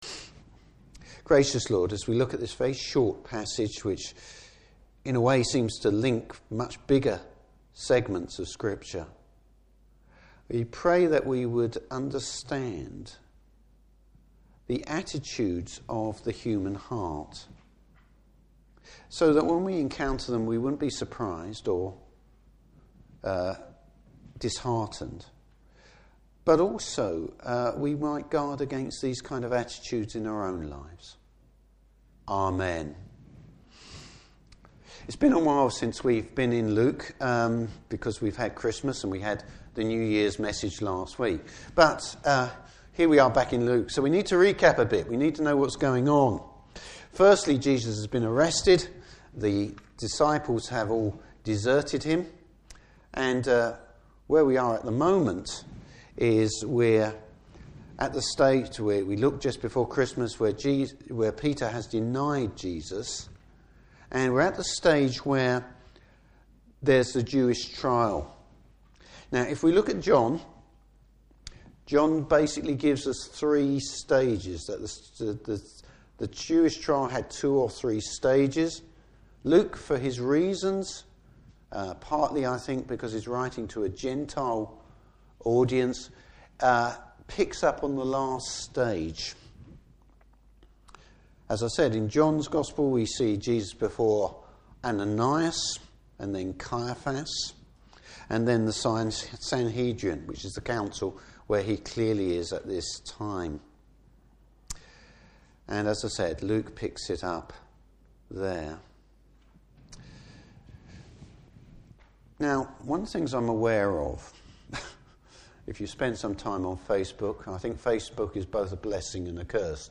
Service Type: Morning Service Reasons for unbelief.